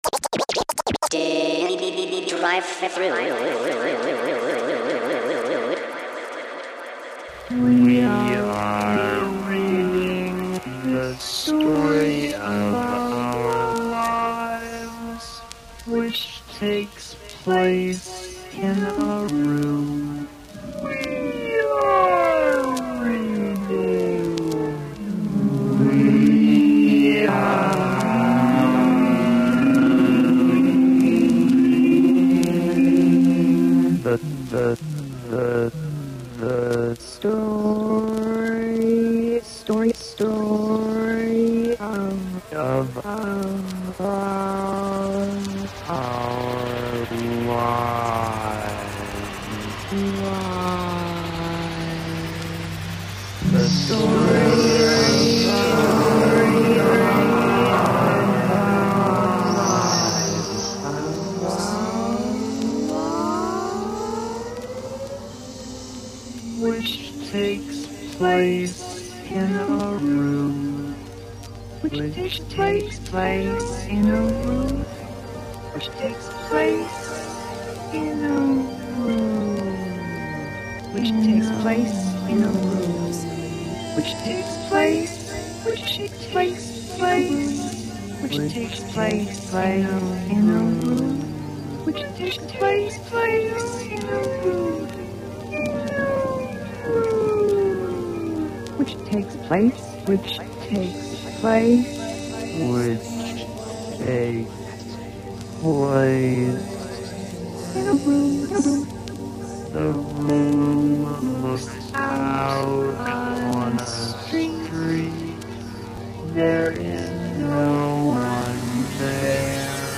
Haunting, mystical and downright badass.
Twisting and unknown, it’s a labyrinth of hip hop.